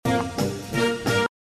I realize these "tones" are probably put in the song to keep folks from doing other things with it - that's fine.
Use a strong notch filter at 1 KHz.
tone2.mp3